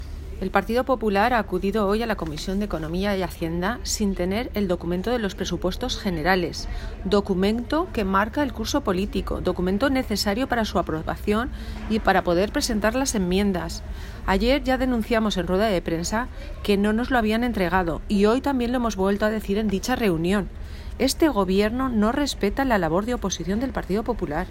Palabras de María Cristina Moreno, concejala del Partido Popular denunciando la falta de información sobre los presupuestos